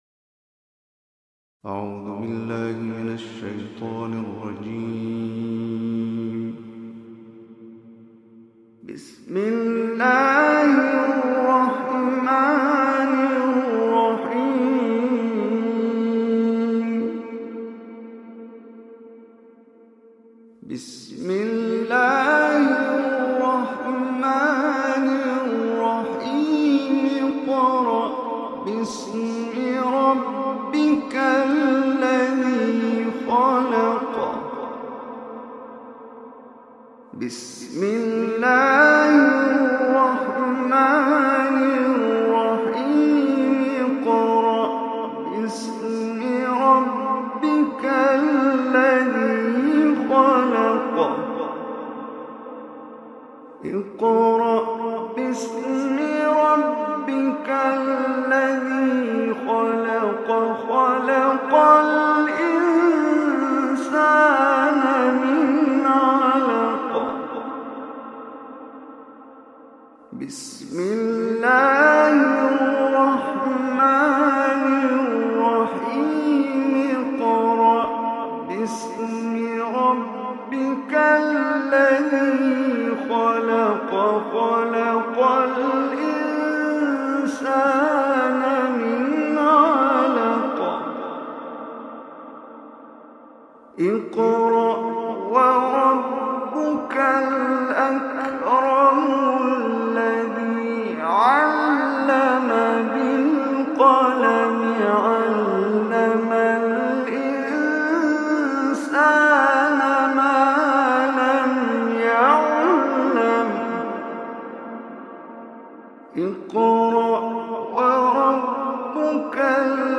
Surah Alaq MP3 Recitation